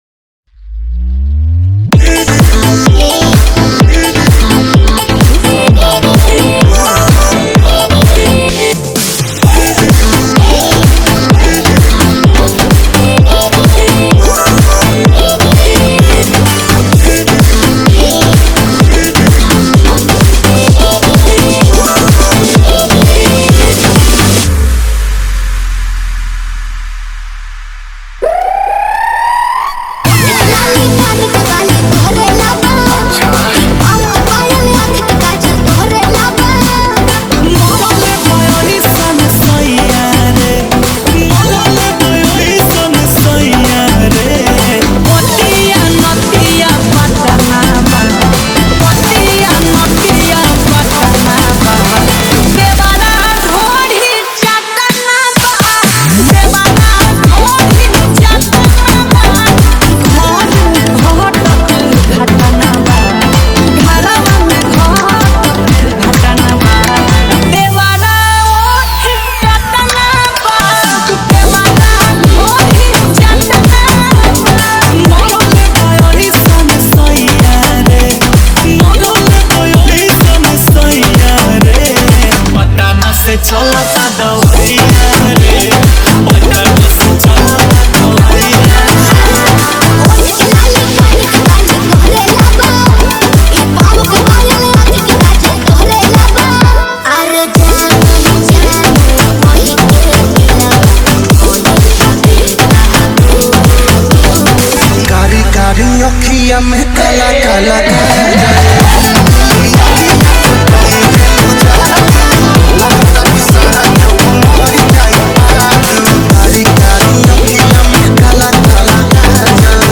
Shaadi Bass Remix Bhojpuri Remix Dj Songs